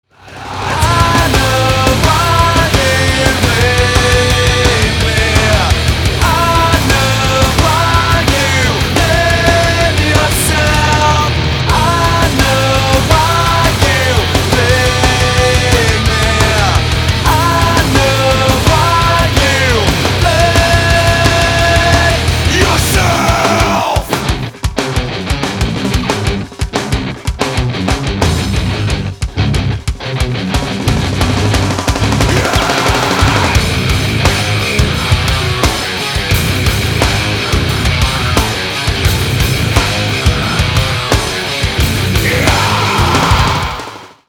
• Качество: 320, Stereo
мужской голос
громкие
жесткие
брутальные
Драйвовые
nu metal
groove metal
ню-метал